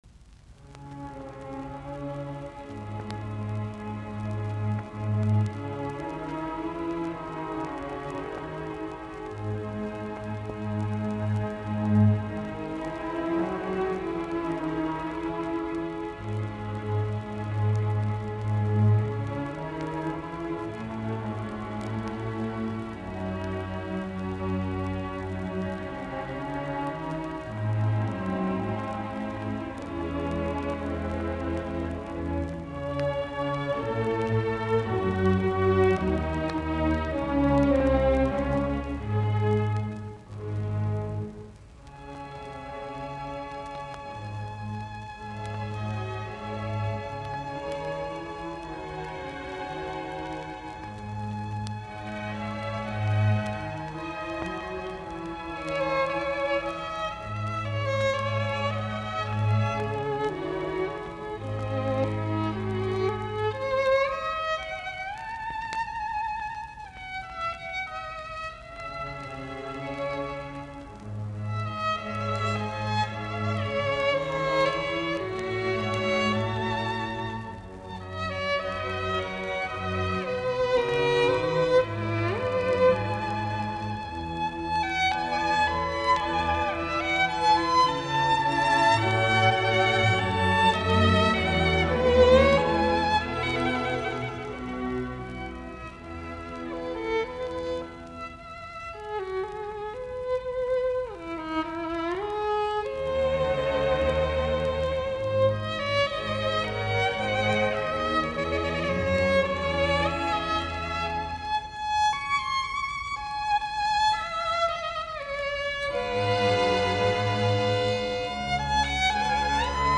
Konsertot, viulu, jousiork.
Soitinnus: Viulu, ork.